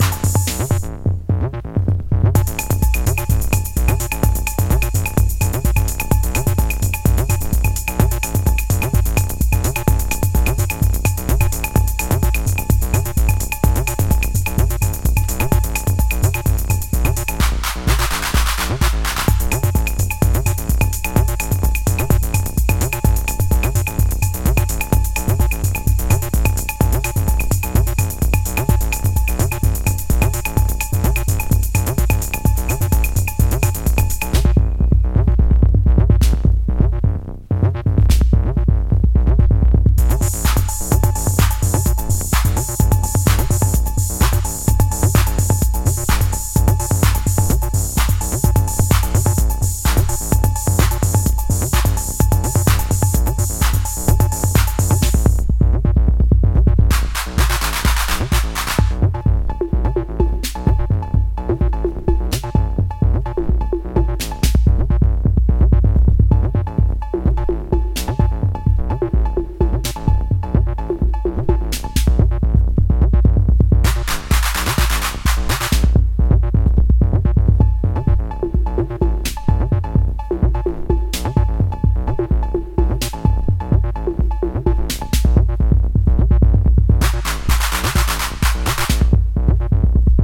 4 raw stripped down Chicago acid cuts